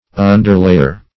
Underlayer \Un"der*lay`er\, n.